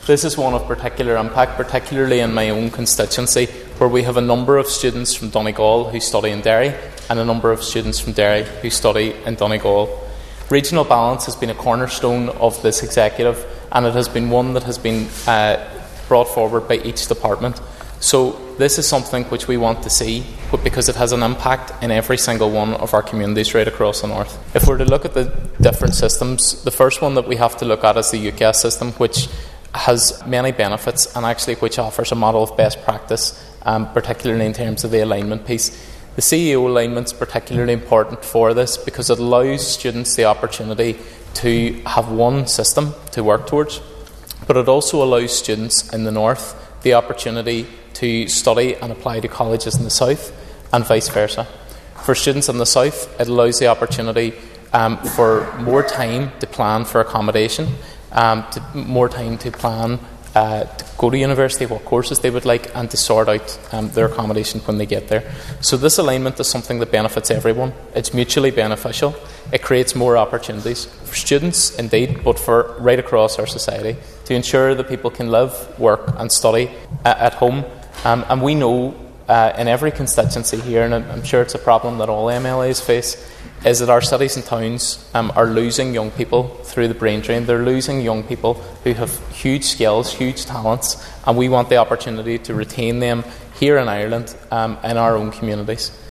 Mr Delargey told the Assembly this is particularly relevant in the North West………..